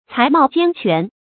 才貌兼全 cái mào jiān quán
才貌兼全发音